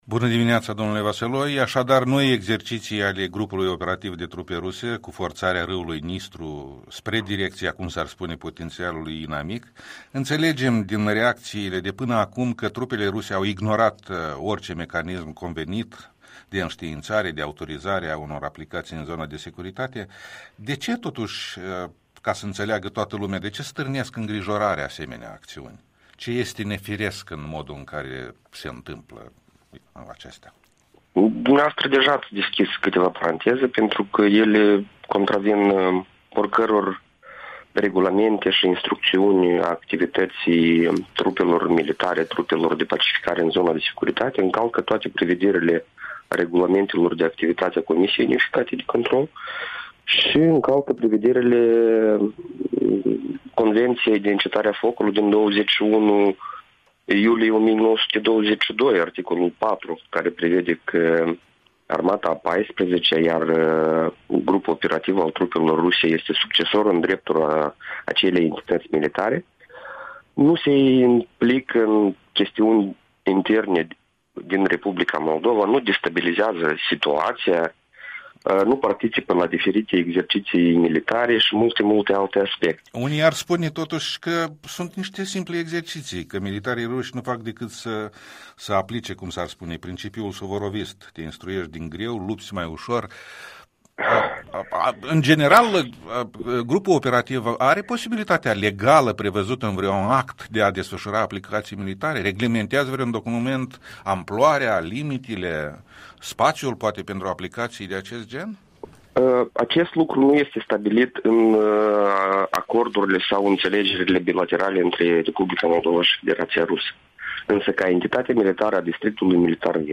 Interviul matinal cu un expertul în politici de securitate de la IDIS Viitorul.